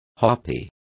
Complete with pronunciation of the translation of harpy.